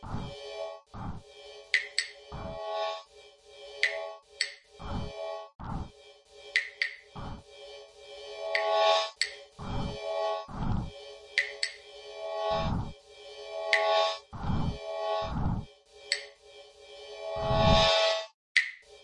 Tag: 鼓循环 常规 的PERC 垃圾 木材 打击乐器环 量化100bpm 节拍 敲击 垃圾 即兴 节奏 PERC